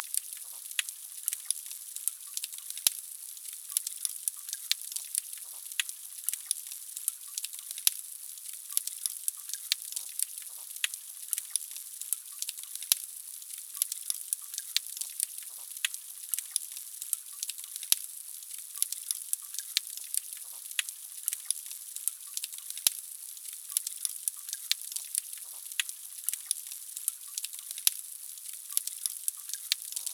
Audio N°1 - Les crevettes claqueuses.wav
6_Crevette_claqueuse_PNMI.wav